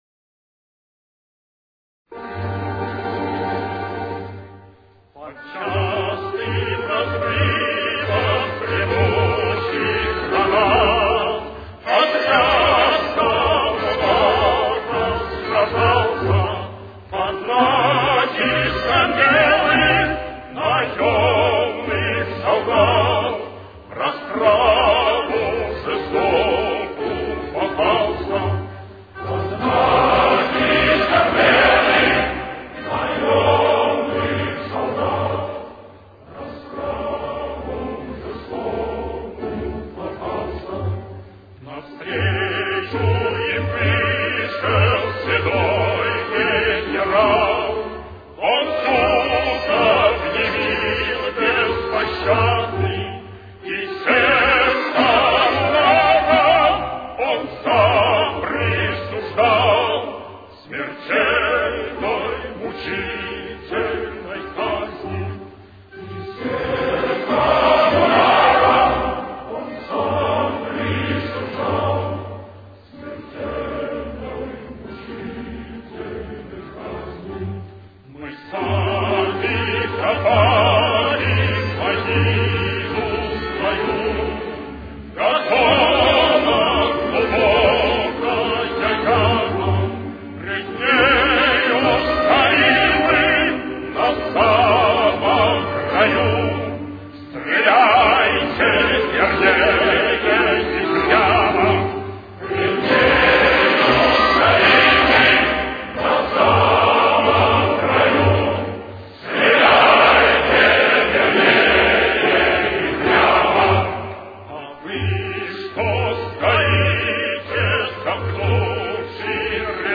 Темп: 70.